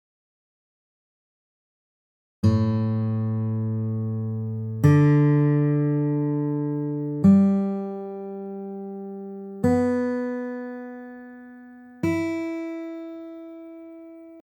肝心のギターのチューニングの音名ですが、６弦からEADGBEとなっています。
下記ダウンロードよりチューニングの実音を貼り付けておりますのでご活用ください。
チューニング実音　ダウンロード
チューニング音_最新.mp3